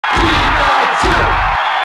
Pikachu's cheer in the US and PAL versions of Smash 64.
Pikachu_Cheer_International_SSB.ogg